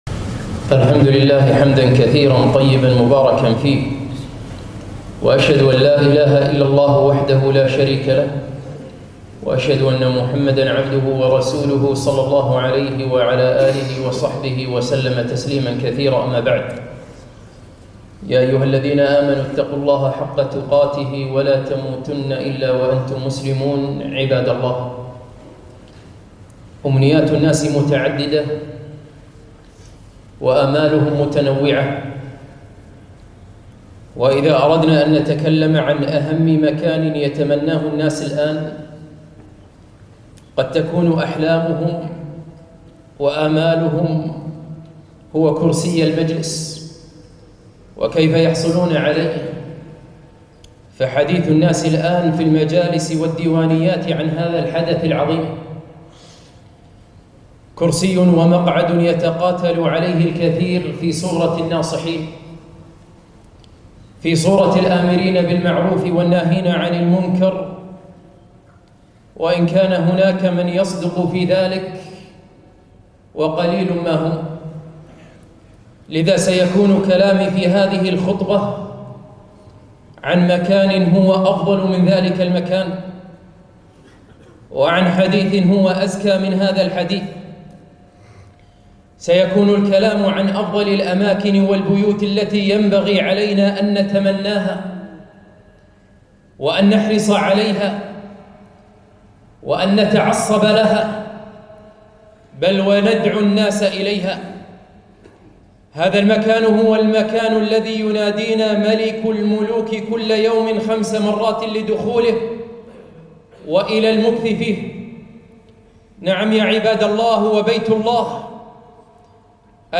خطبة - اللهم وفقني للفوز بهذا المجلس !